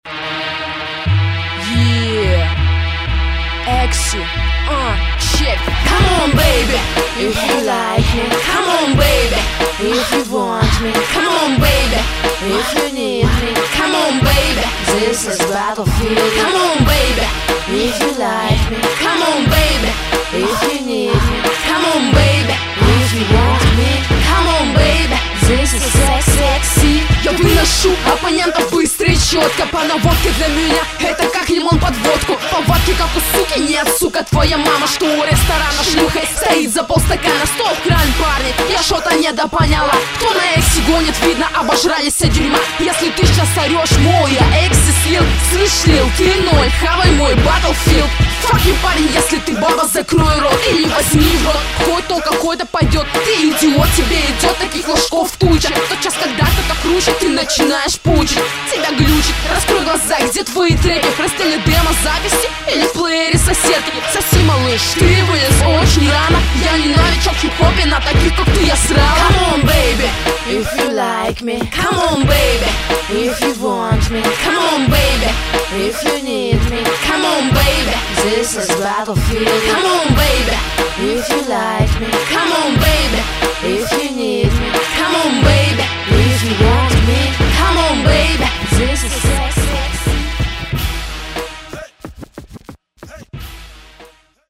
Рэп (46715)